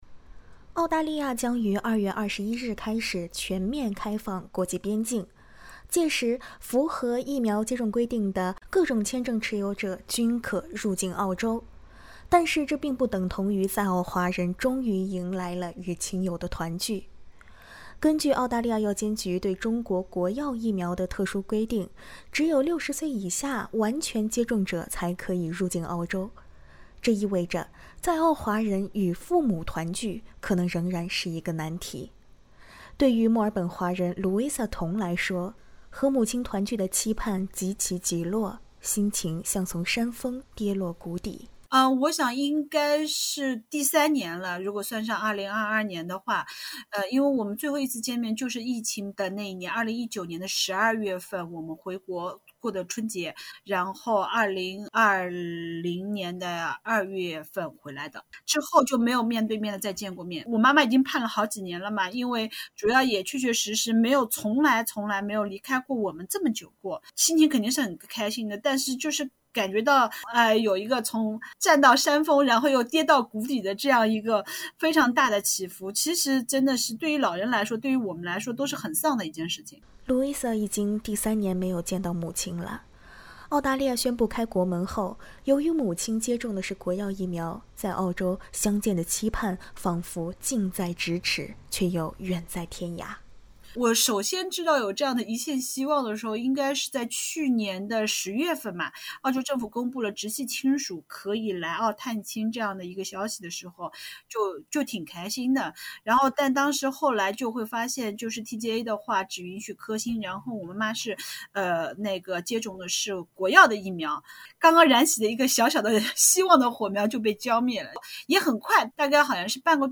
澳洲宣布2月21日全面打开国境，但是，还有一批已完全接种疫苗的人群却依旧被卡在入境门槛之外。（点击上图收听采访）